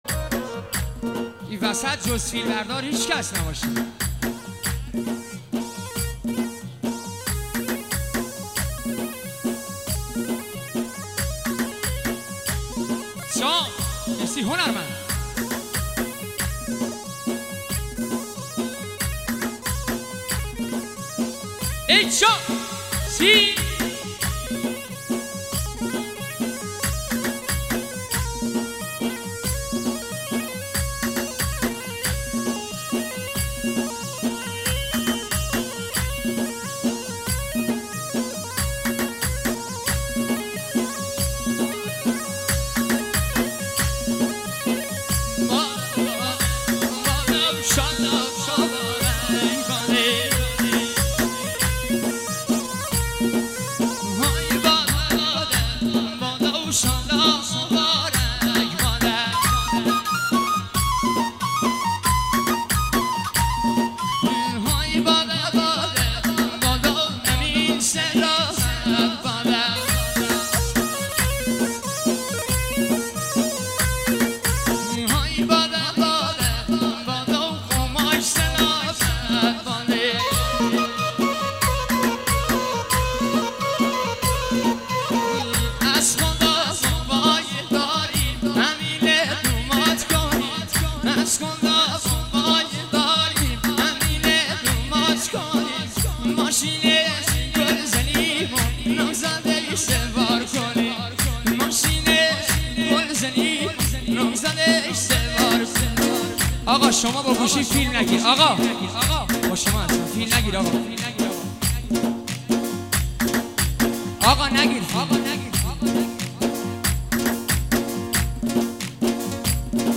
اهنگ لری